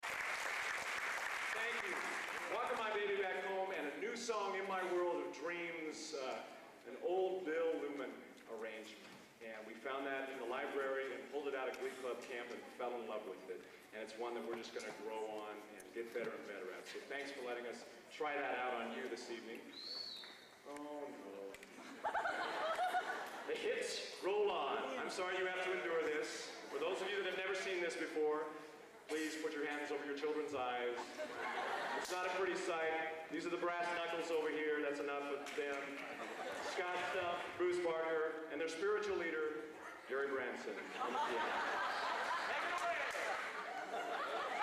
Location: Purdue Memorial Union, West Lafayette, Indiana
Genre: | Type: Director intros, emceeing